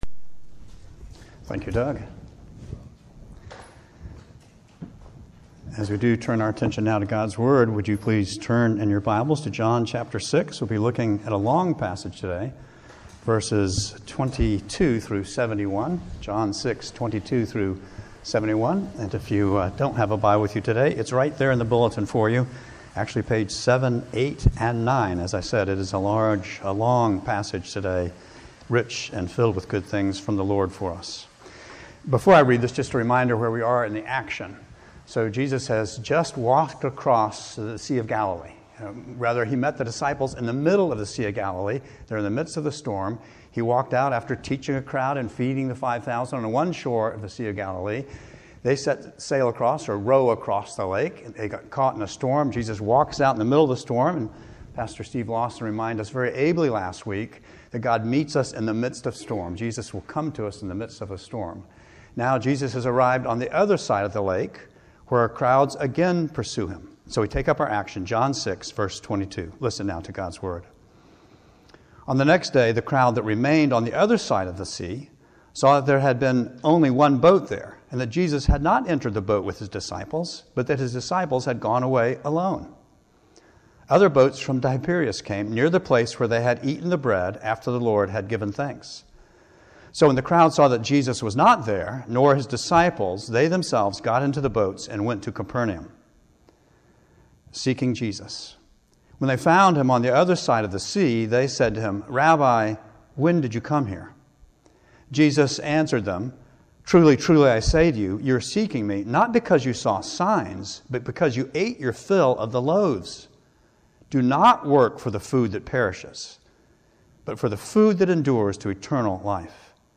Sermons | Christ Presbyterian Church